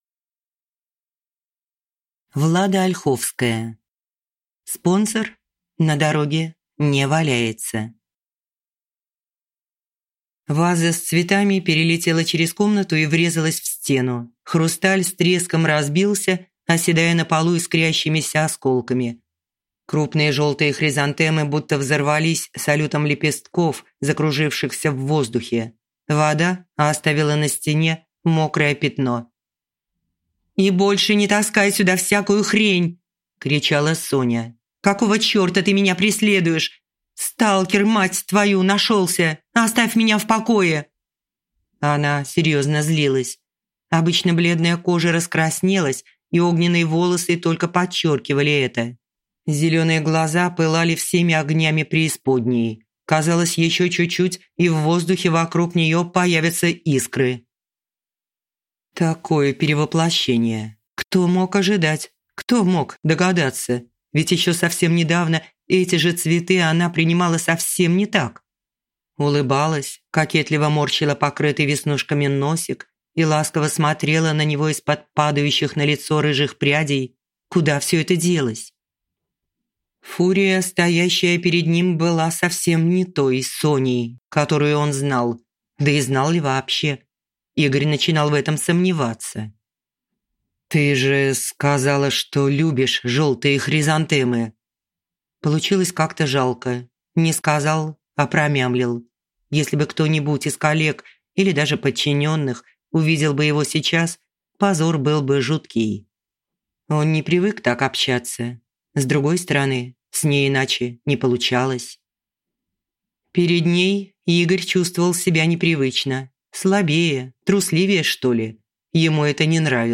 Аудиокнига Спонсор на дороге не валяется | Библиотека аудиокниг